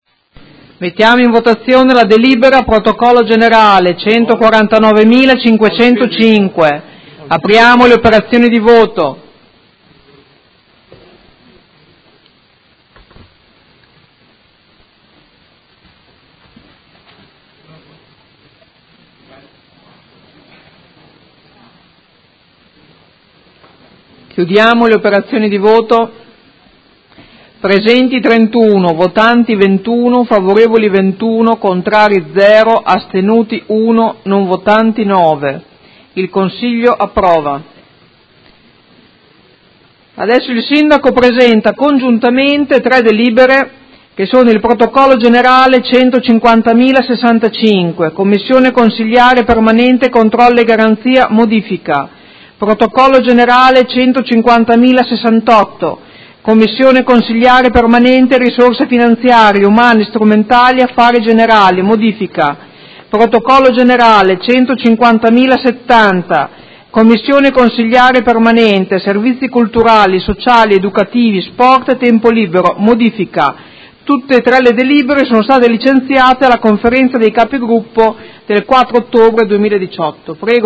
Presidentessa — Sito Audio Consiglio Comunale